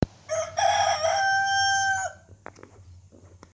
Cockerell crowing ringtone free download
Animals sounds